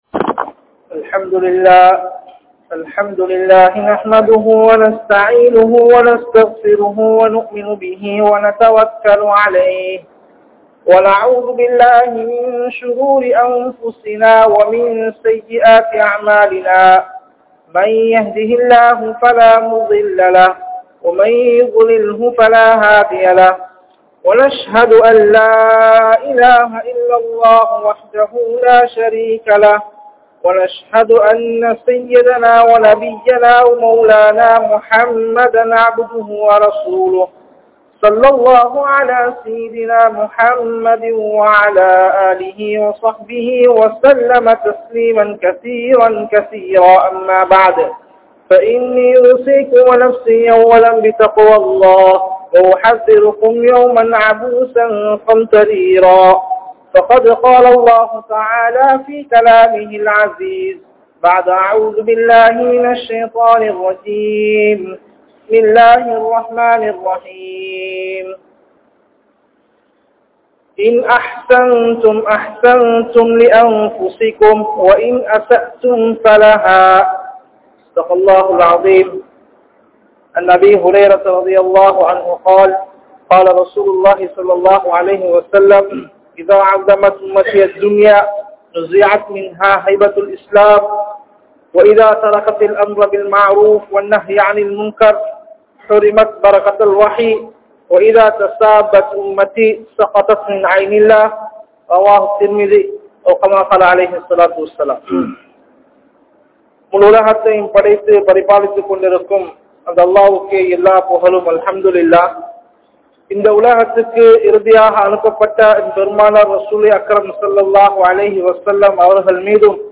Paavaththai Vittu Vidungal (பாவத்தை விட்டு விடுங்கள்) | Audio Bayans | All Ceylon Muslim Youth Community | Addalaichenai